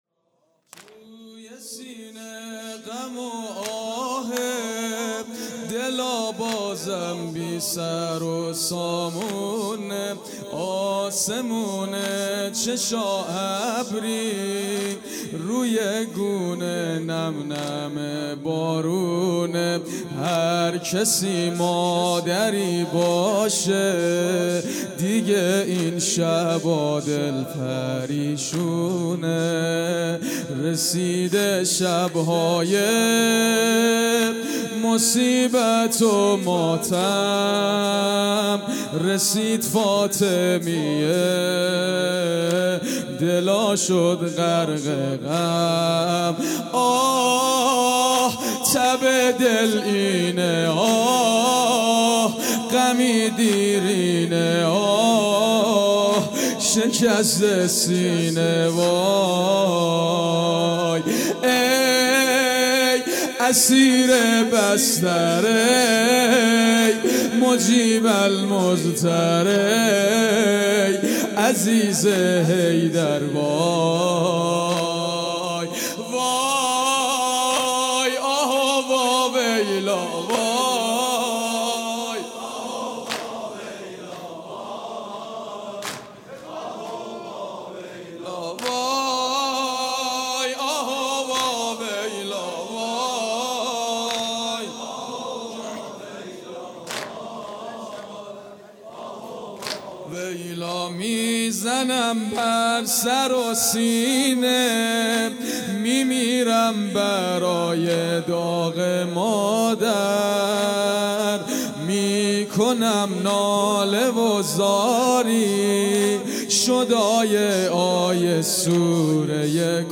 هیئت دانشجویی فاطمیون دانشگاه یزد
توی سینه غم و آهه|شب چهارم فاطمیه ۹۴